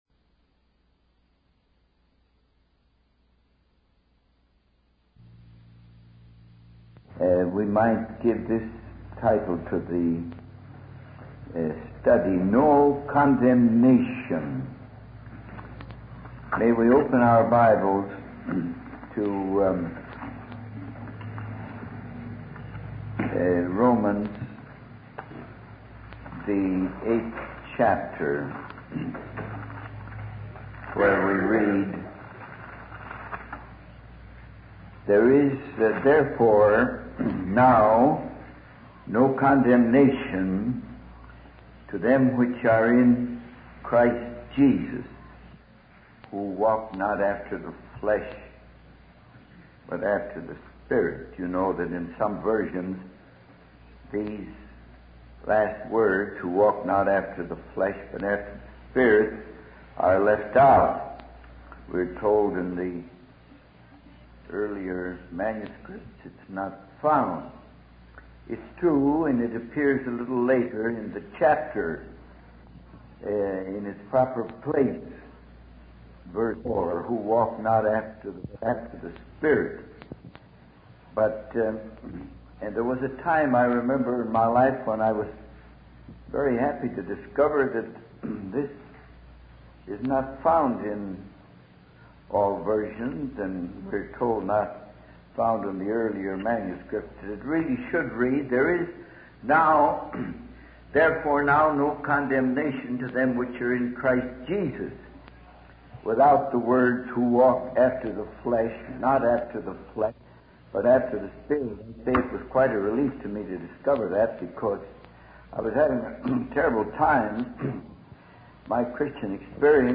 In this sermon, the speaker discusses the challenges and negative influences of the world. They emphasize the importance of remembering that the blood of Jesus washes away all sins.